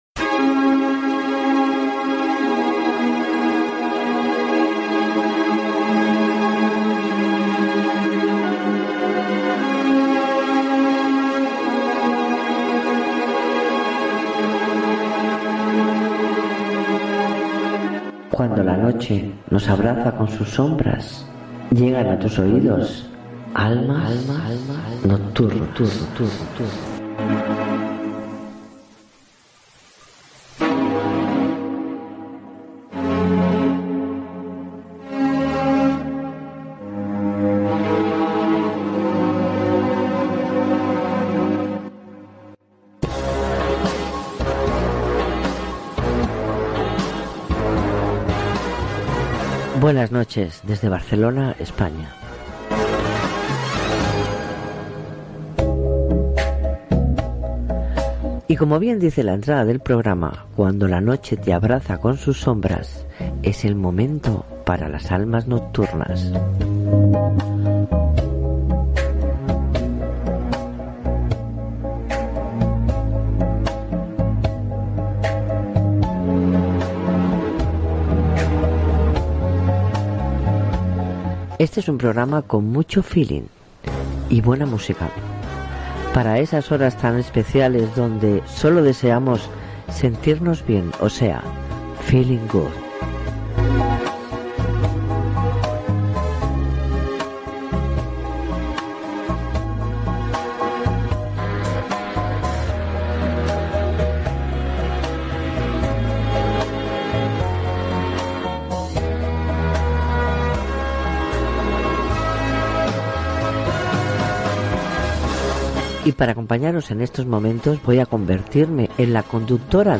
Careta del programa i presentació